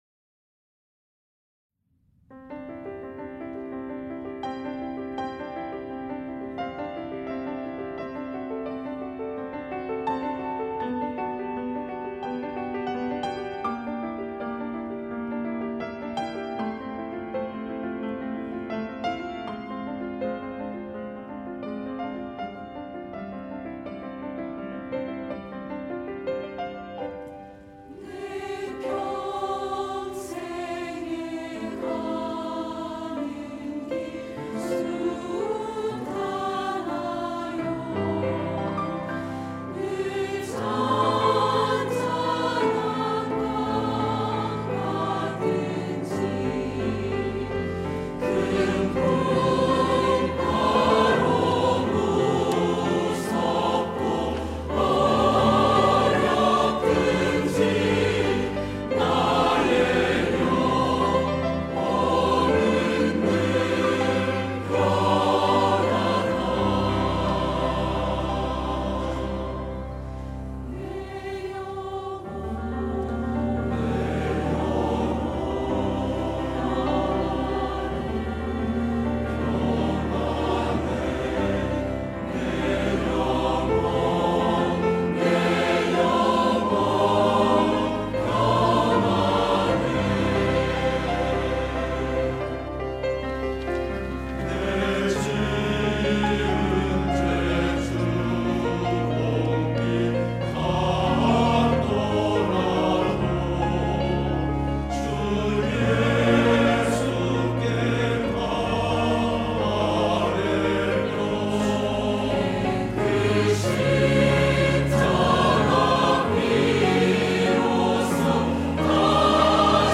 할렐루야(주일2부) - 내 평생에 가는 길